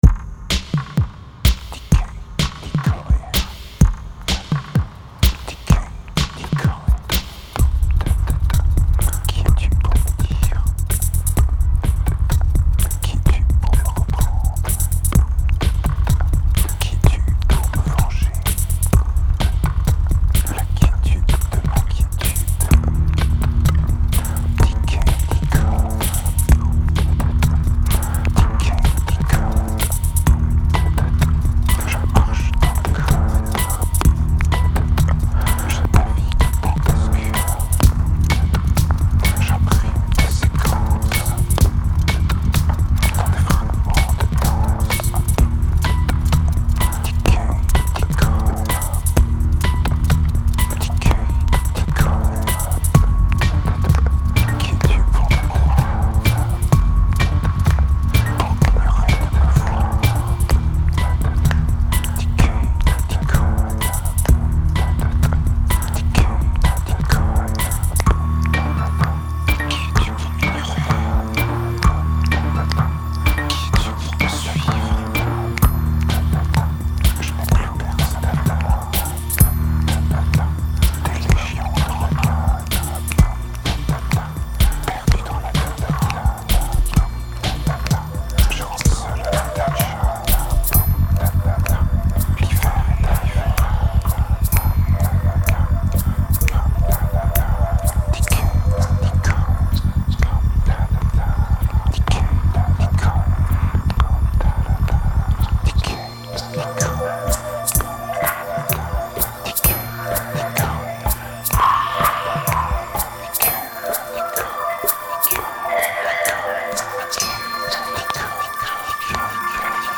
Tight and progressive, it seems to flow out more naturally.
2164📈 - -57%🤔 - 127BPM🔊 - 2008-11-01📅 - -490🌟